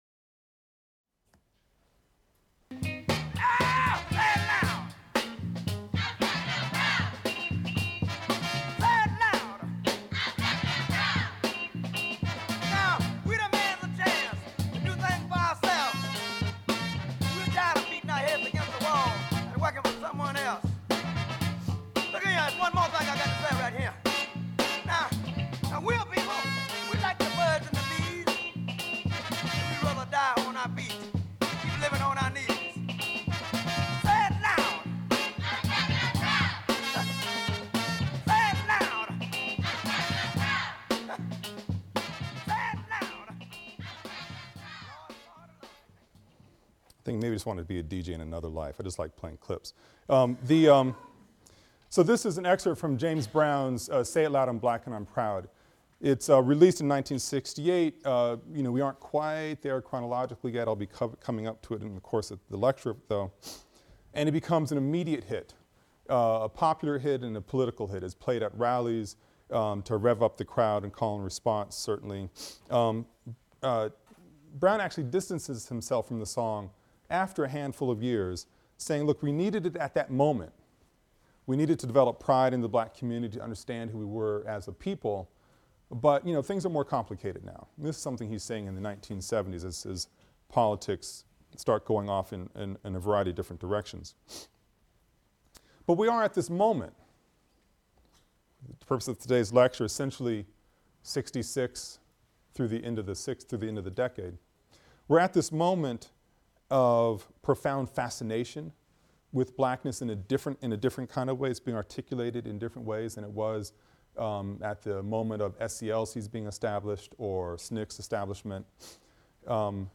AFAM 162 - Lecture 18 - Black Power | Open Yale Courses